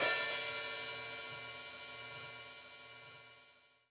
OpenHat.wav